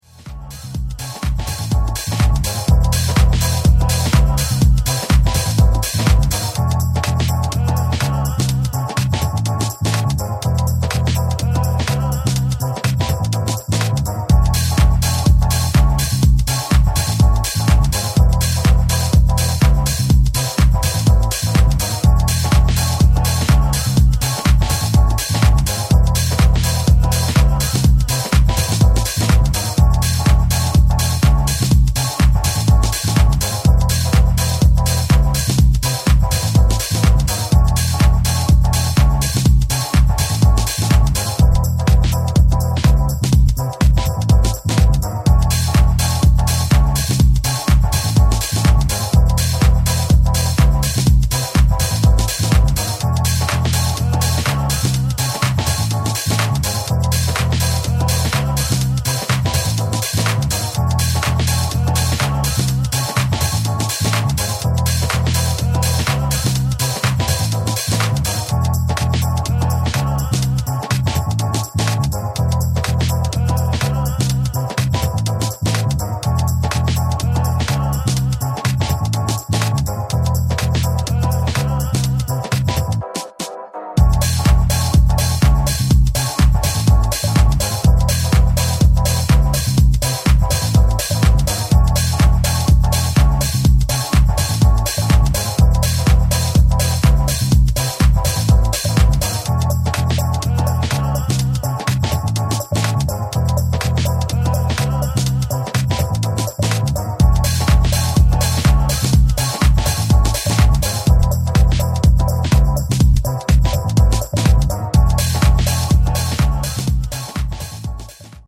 timeless deep house